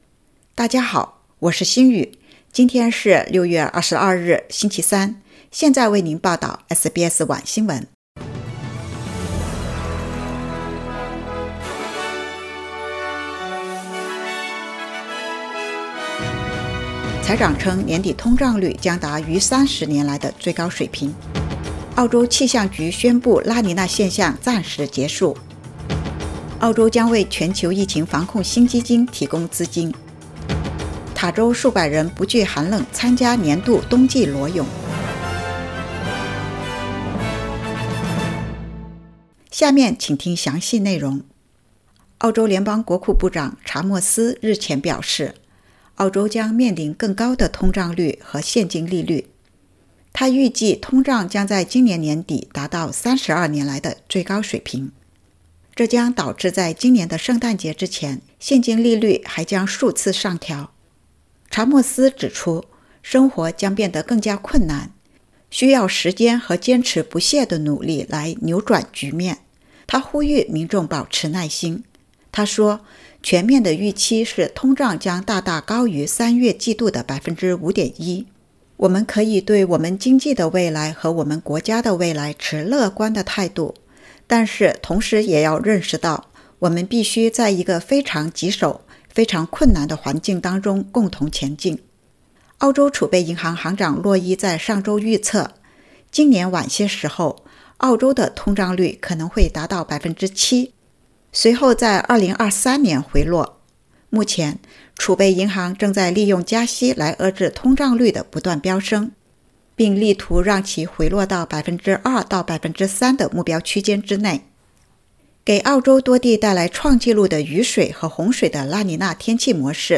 SBS晚新闻（2022年6月22日）
SBS Mandarin evening news Source: Getty Images